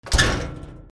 / K｜フォーリー(開閉) / K05 ｜ドア(扉)
マンション扉 乱暴に閉める